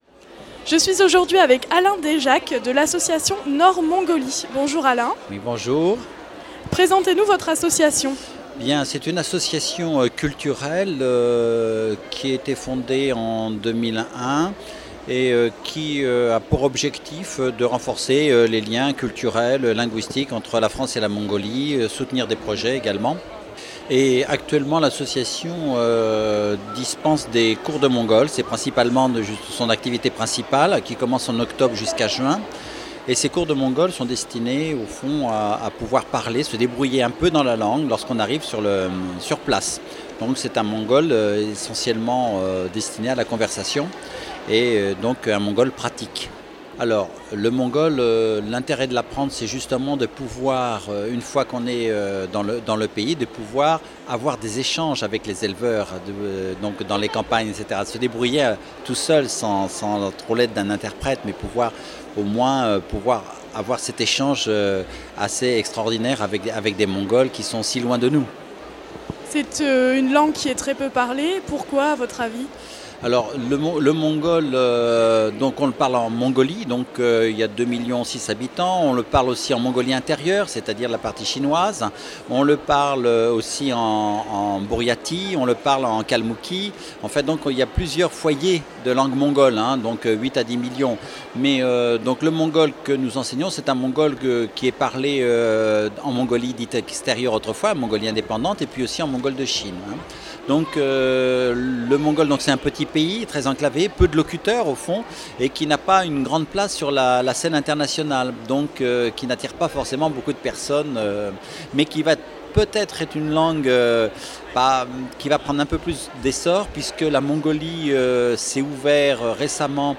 à la CCI de Lille
Interviews réalisées pour Radio Campus